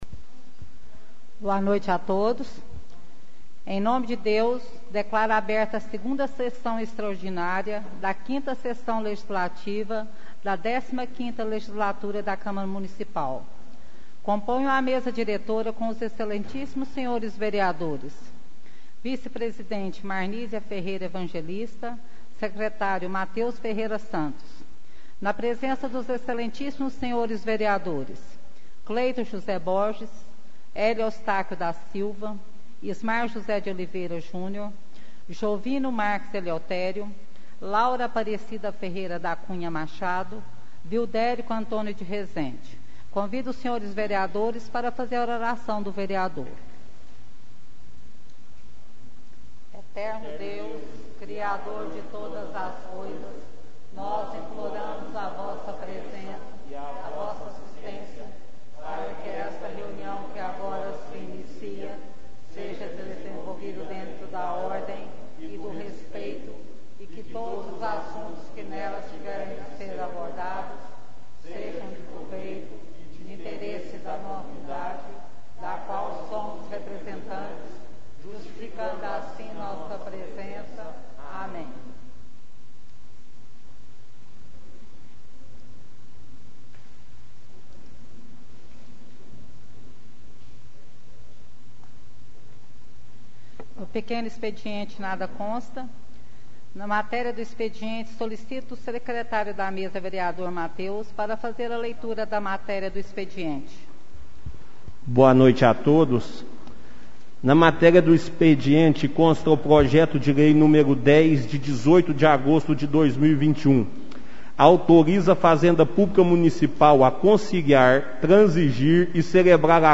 2ª Extraordinária da 1ª Sessão Legislativa da 15ª Legislatura
2a_sessao_extraordinaria_2021.mp3